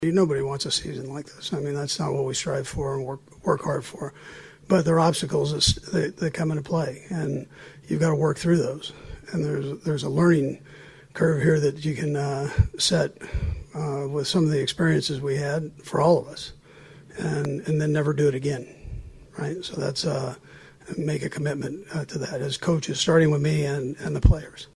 Andy Reid at the conclusion of a season that saw the Chiefs finish with a record of 6-11 including losing their 6th straight game.